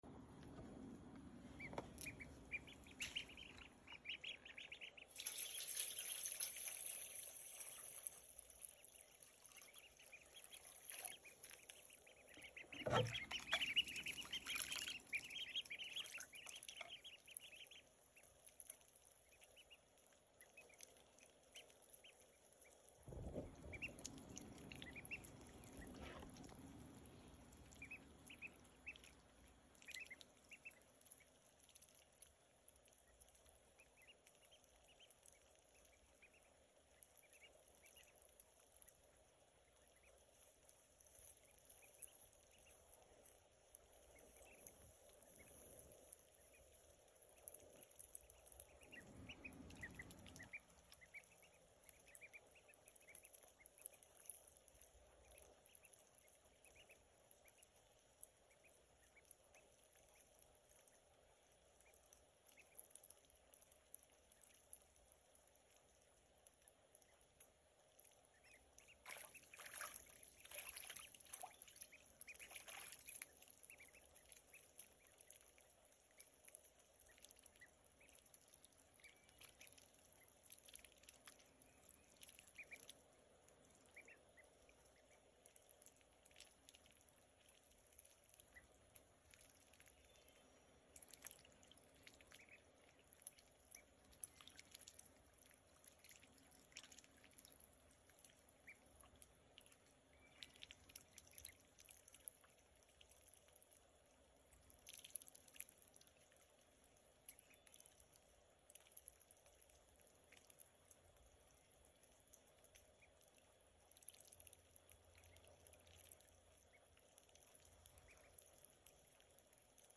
Please enjoy some baby duck sound effects free download
Please enjoy some baby duck ASMR